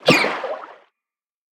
Sfx_creature_seamonkeybaby_flinch_01.ogg